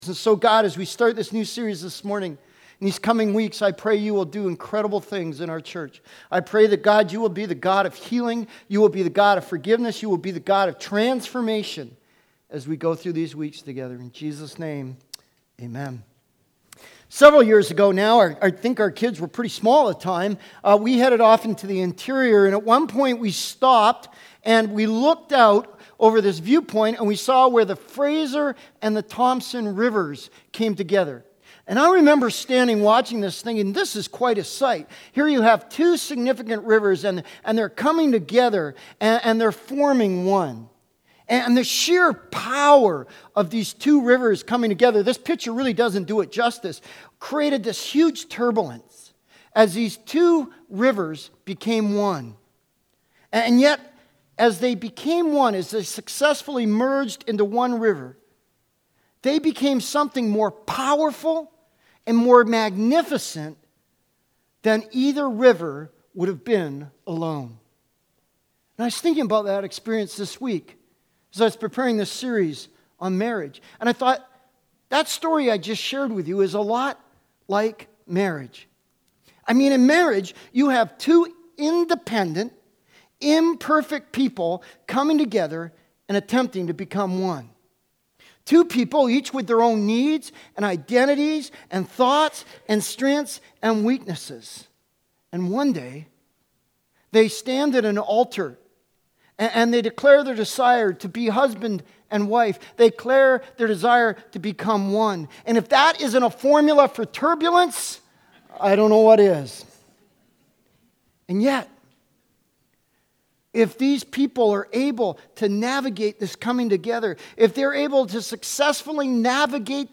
Sermons | Saanich Baptist Church